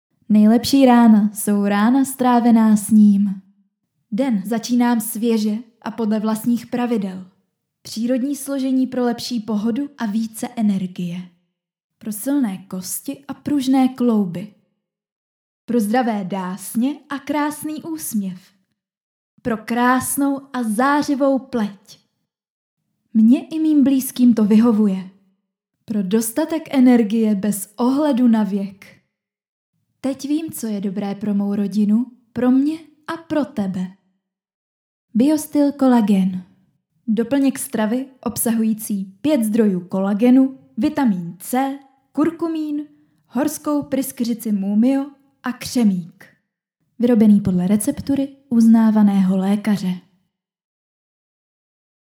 Ženský hlas / voiceover / vokály
Pracuji na svém mikrofonu, ale i ve vašem studiu!